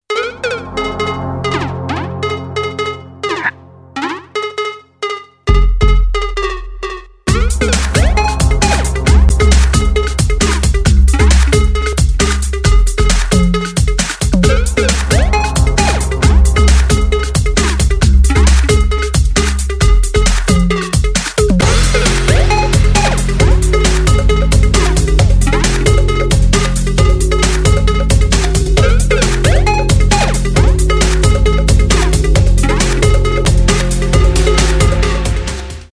техно , без слов
electronic , минимал